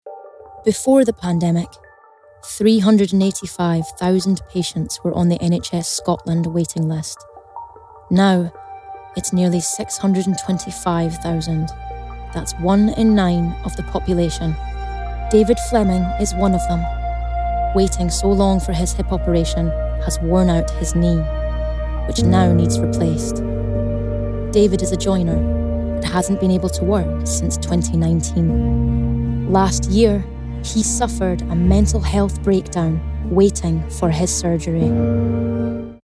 Scottish
Female
Warm